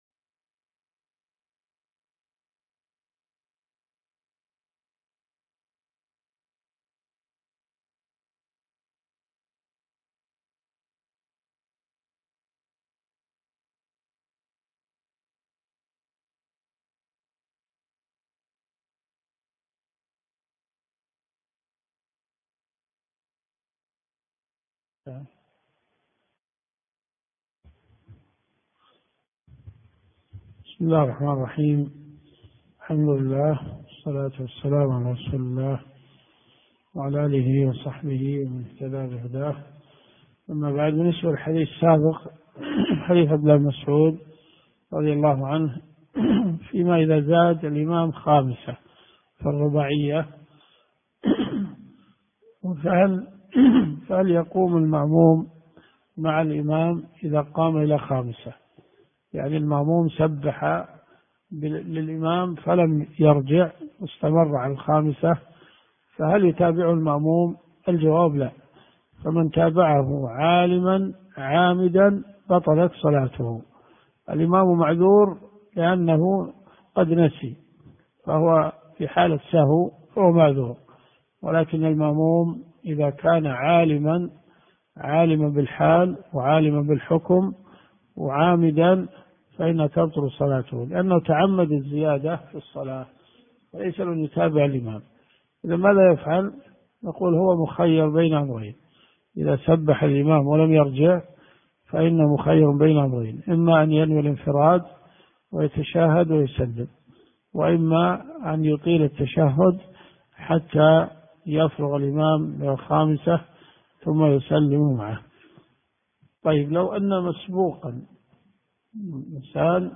صحيح مسلم . كتاب المساجد ومواضع الصلاة . من حديث 1295 -إلى- حديث 1312 . الدرس في الدقيقة 4.25 .